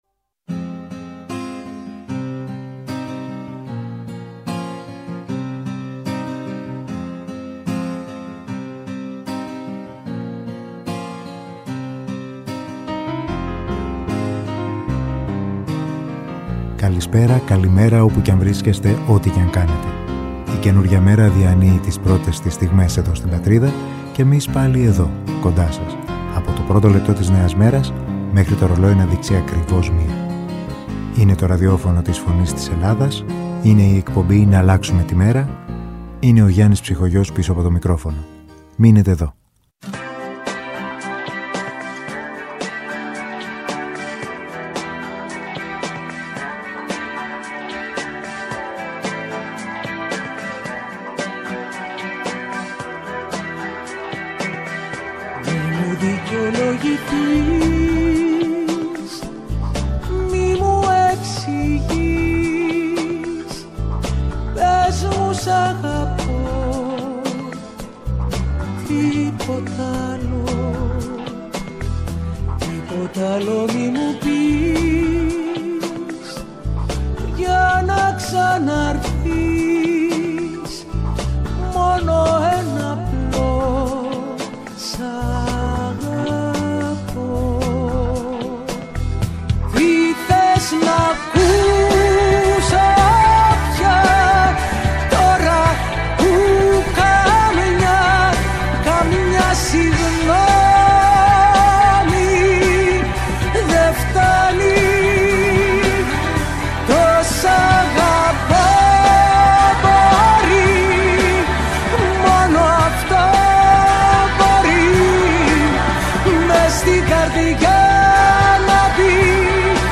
Στην αρχή μιας νέας μέρας με μουσικές
Μουσική